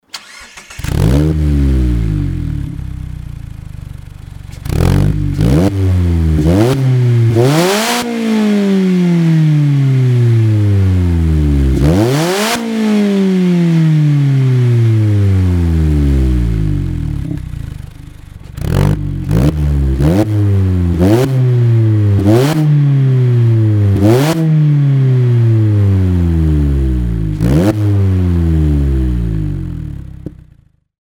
音圧に繋がる低音の音も強くならないようですね。
ダブルバッフルサイレンサー
ダブルバッフル改良4穴サイレンサー